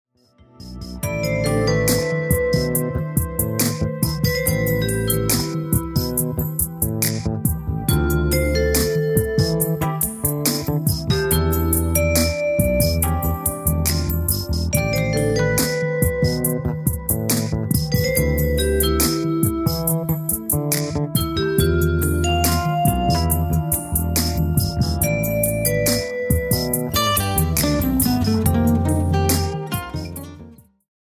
A play-along track in the style of fusion.
It is in the style of fusion.